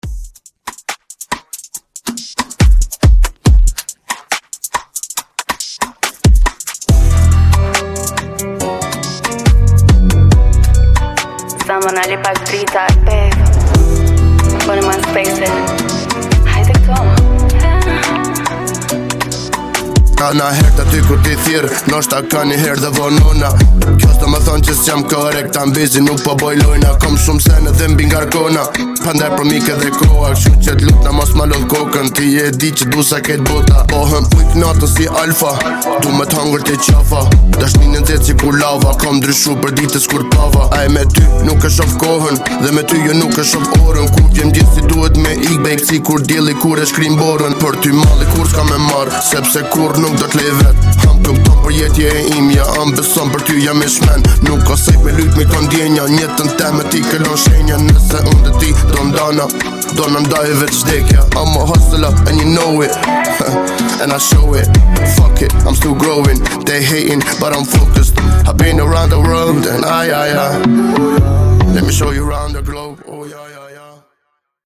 Genre: DANCE
Clean BPM: 128 Time